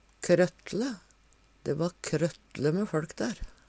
krøttle - Numedalsmål (en-US)